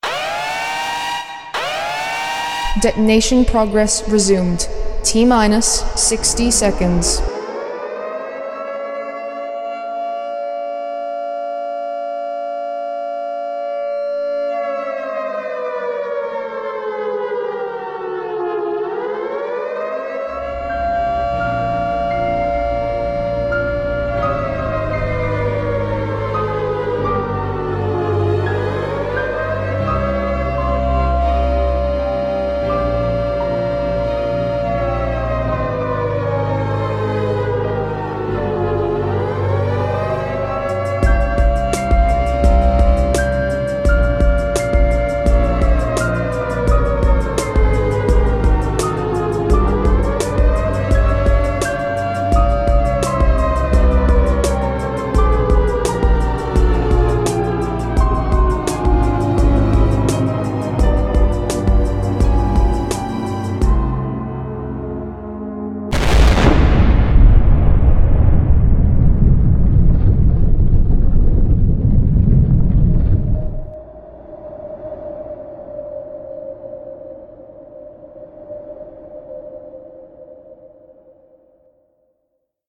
FemaleResume60.mp3